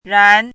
chinese-voice - 汉字语音库
ran2.mp3